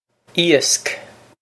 iasc ee-osk
This is an approximate phonetic pronunciation of the phrase.